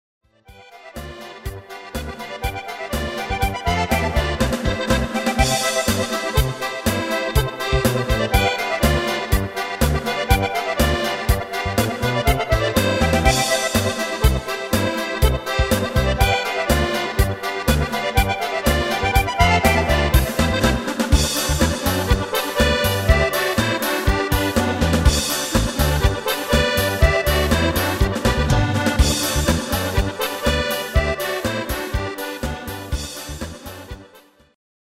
Basic MIDI File Euro 8.50